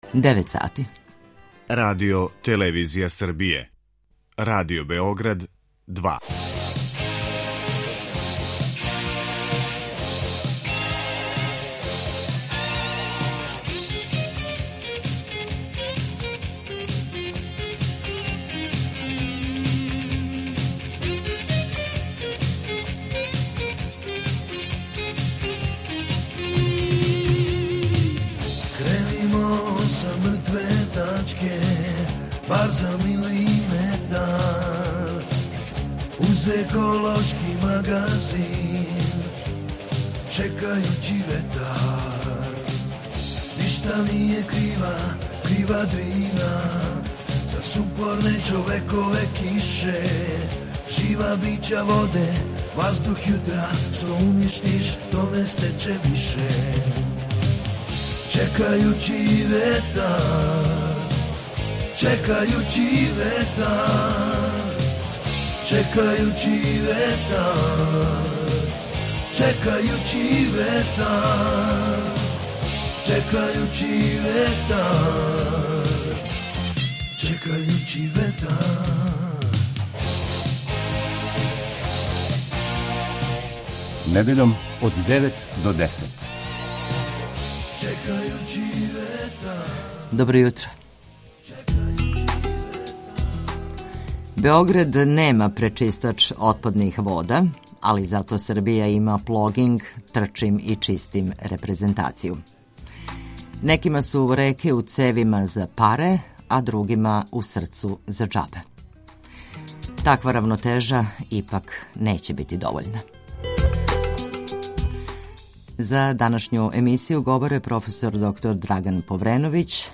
ОВДЕ Чекајући ветар - еколошки магазин Радио Београда 2 који се бави односом човека и животне средине, човека и природе.